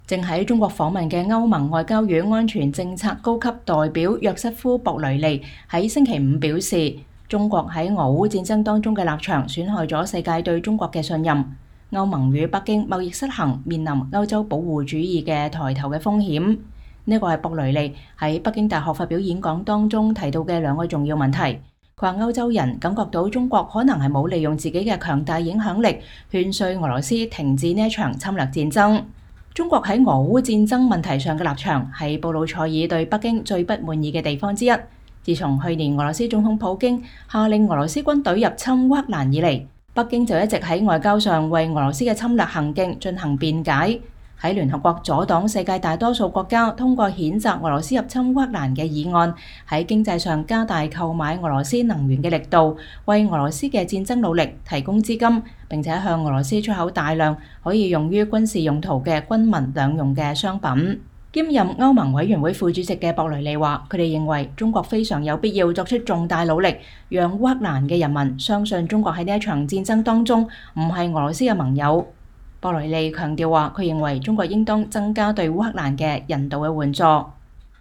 博雷利北大演講：中國對俄烏戰爭的立場損害了世界對中國的信任